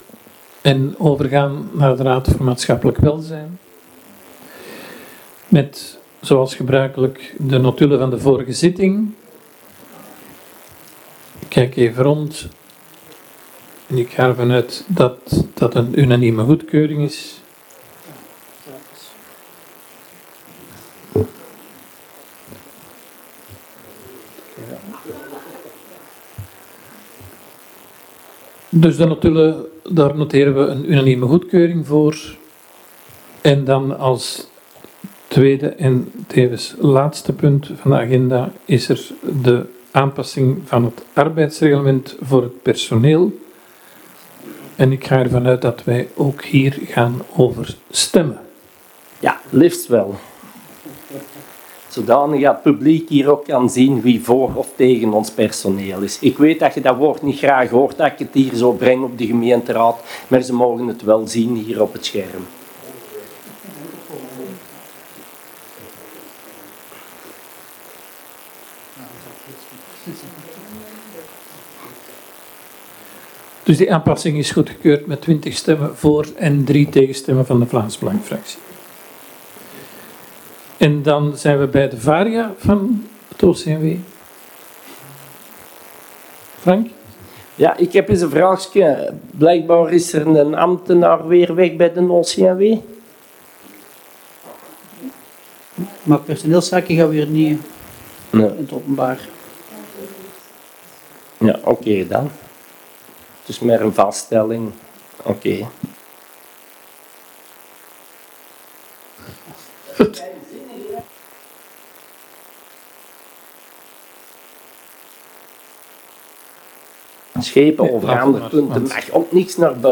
Gemeentehuis